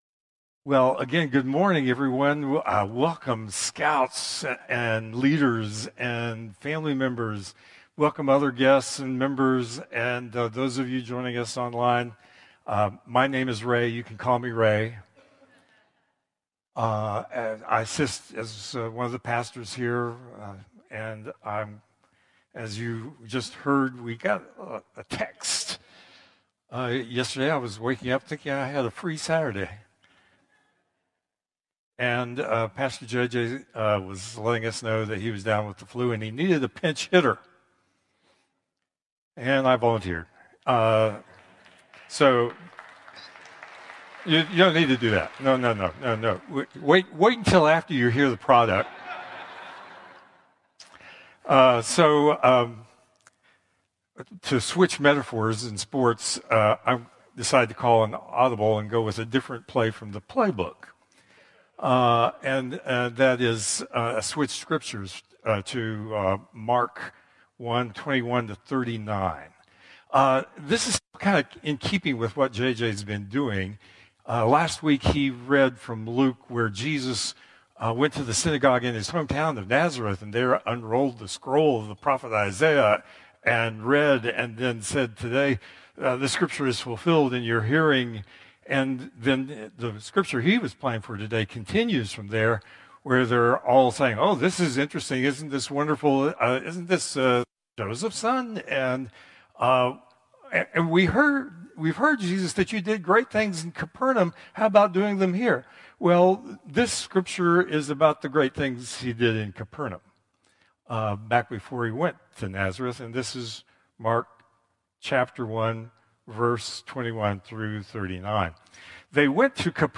Contemporary Service 2/2/2025